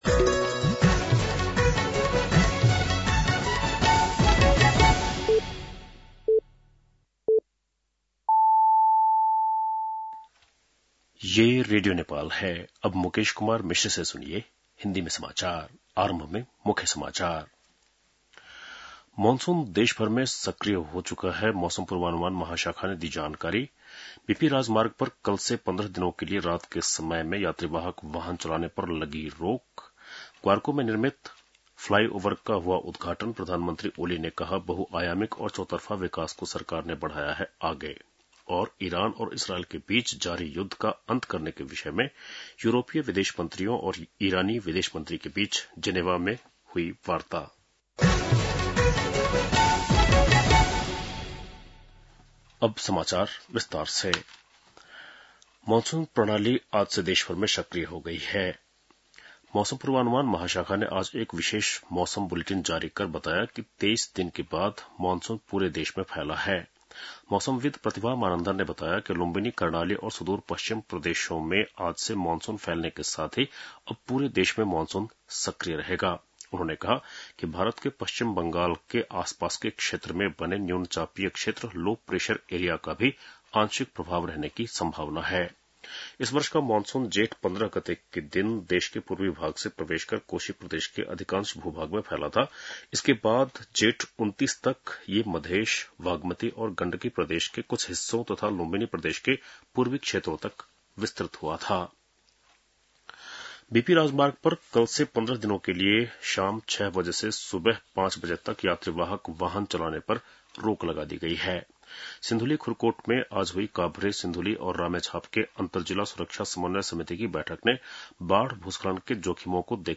बेलुकी १० बजेको हिन्दी समाचार : ६ असार , २०८२